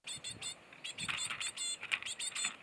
巣立ち後しばらくは親から餌をもらっていて、そのころの鳴き声は始期終期かそろった多重音 を出せるようになる。